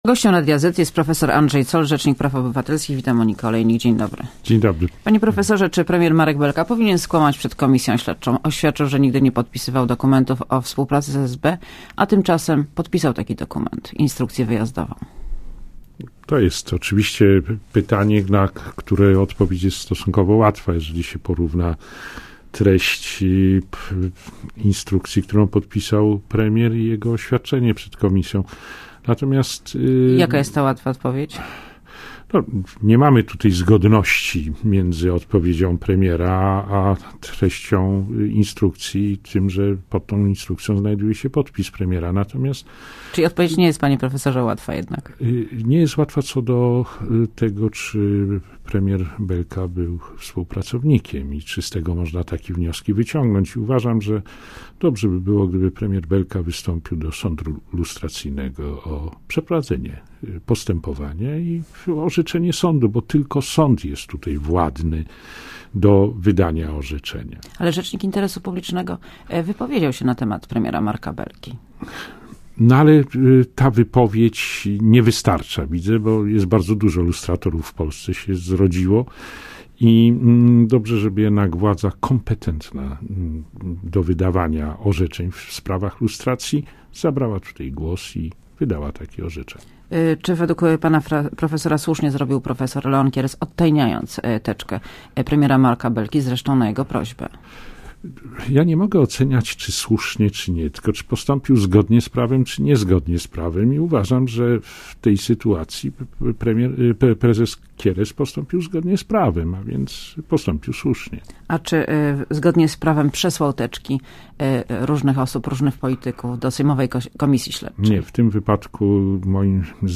prof.Andrzej Zoll, Rzecznik Praw Obywatelskich.